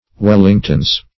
Search Result for " wellingtons" : The Collaborative International Dictionary of English v.0.48: Wellingtons \Wel"ling*tons\, n. pl.